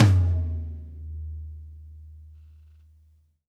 Tom Shard 03.wav